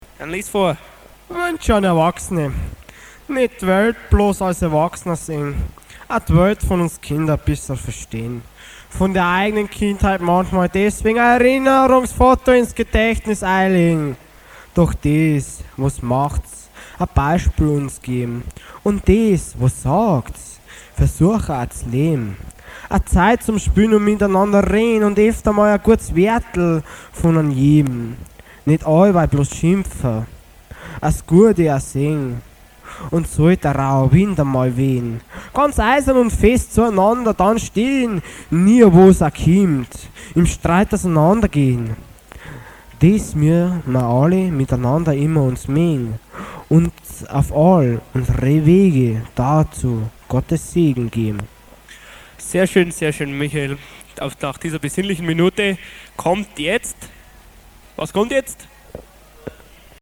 4. Gedicht – Wünsche an Erwachsene – Frohes Fest! mit der EdH-Crew (1995)